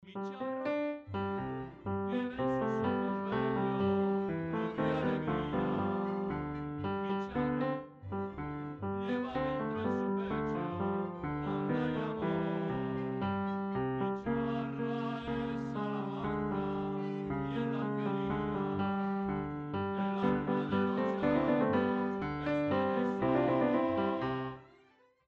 Acordeón